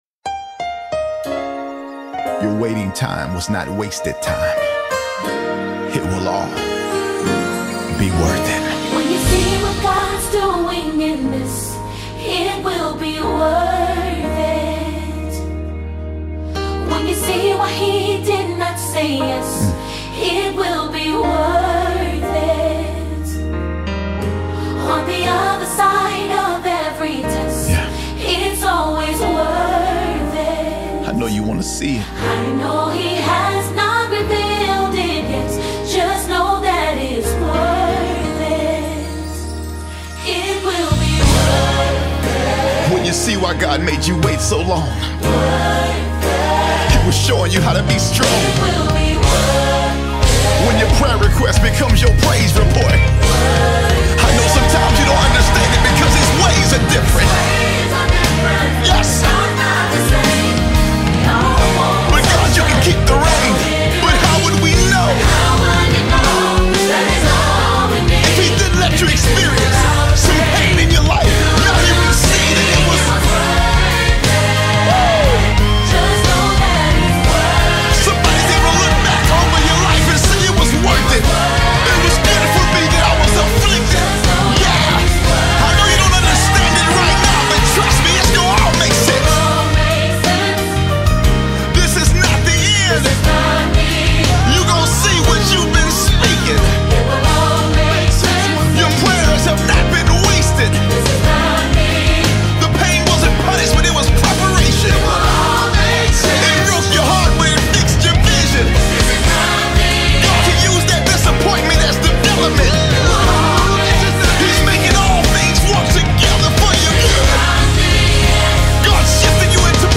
Mp3 Gospel Songs
the American gospel sonster
America’s top-rated gospel singer